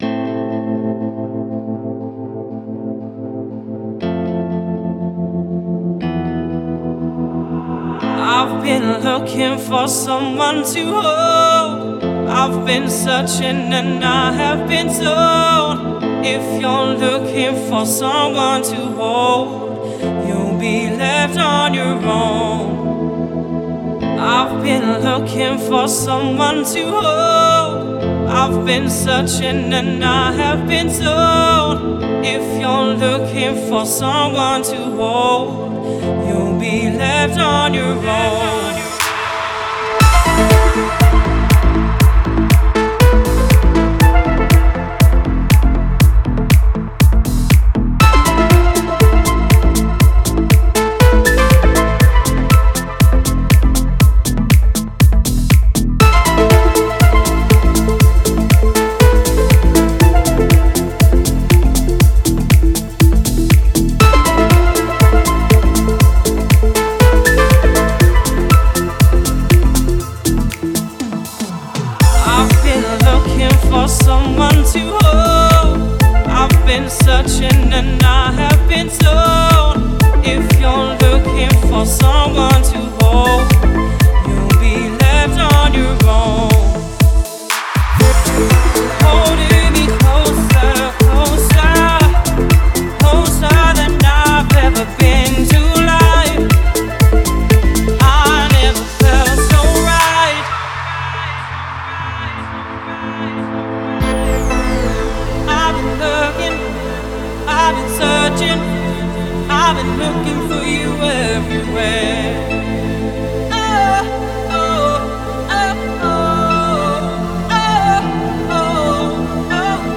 это эмоциональная электронная композиция в жанре deep house